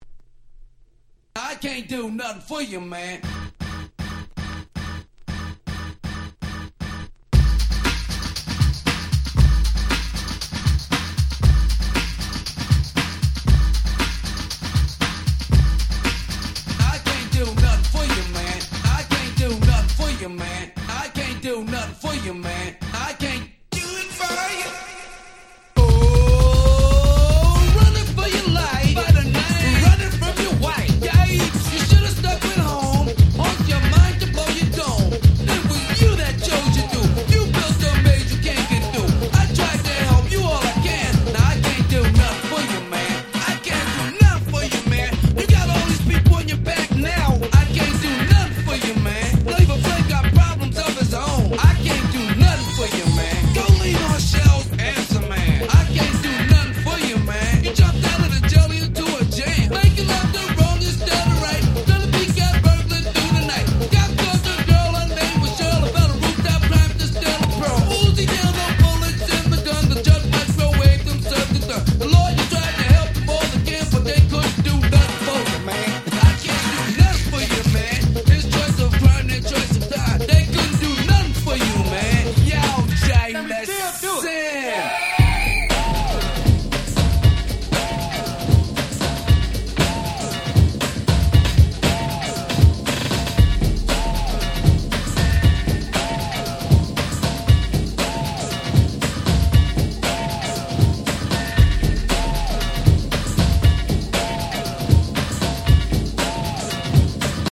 90' Smash Hit Hip Hop !!